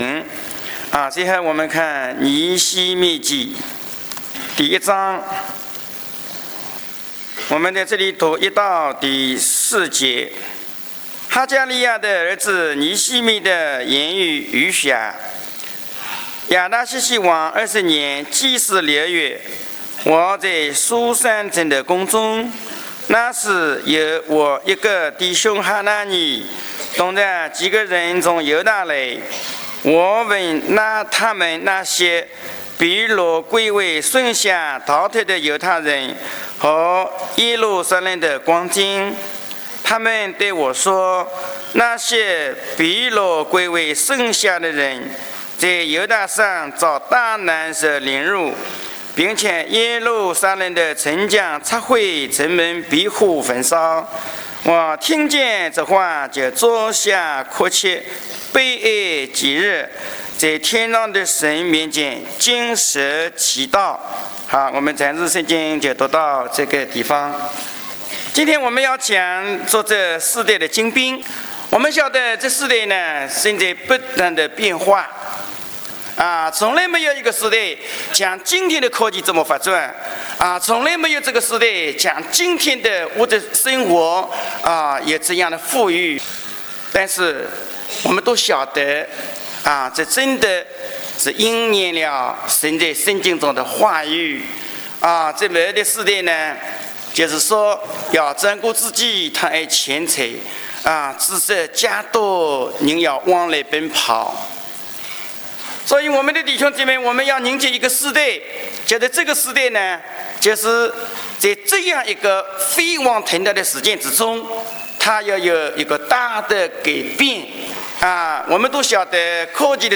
特会信息